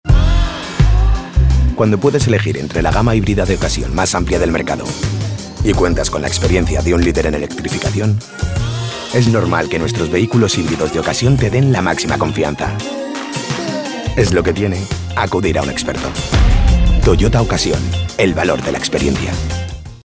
Publicitario Commercials -